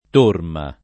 t1rma] s. f. («schiera») — antiq. in generale la forma latineggiante turma [t2rma], ancóra usabile però come term. stor. («reparto di cavalleria»): Qualche turma di numidi cavalli [kU#lke tt2rma di n2midi kav#lli] (Pascoli)